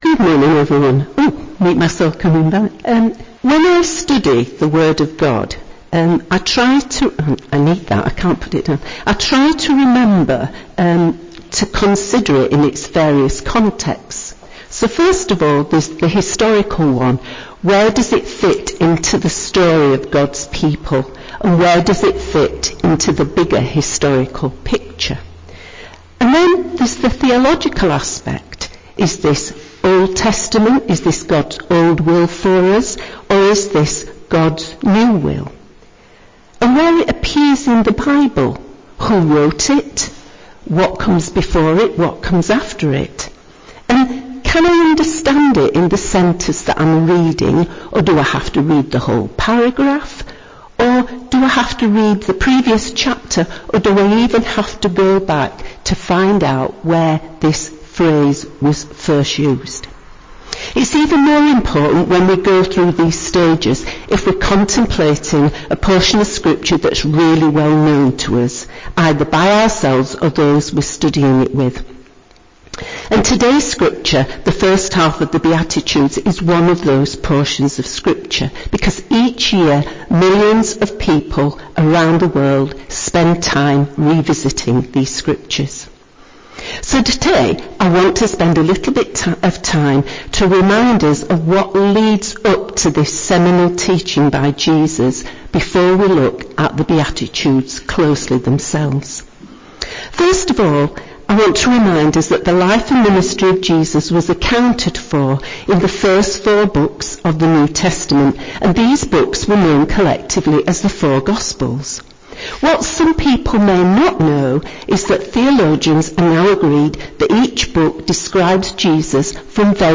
Listen and download previous sermons given at EBC
Summer Sermons